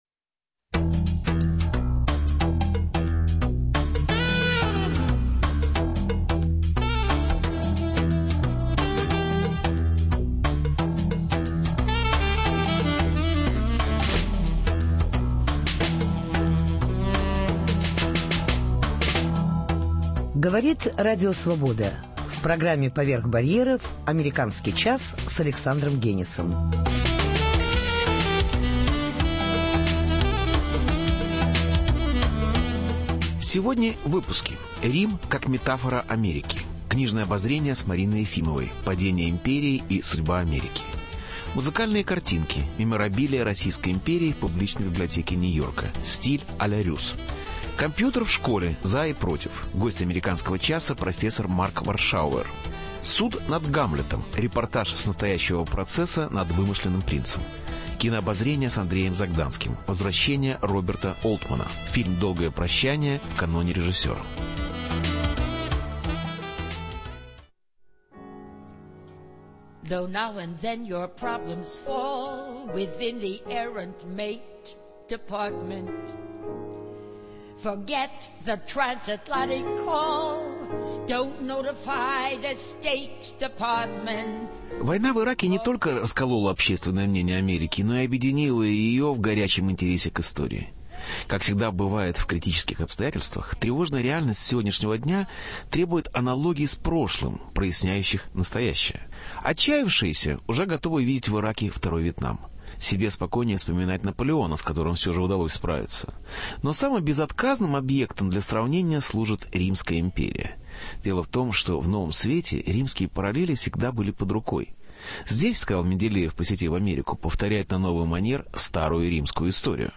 Суд над Гамлетом. Репортаж с настоящего процесса над вымышленным принцем.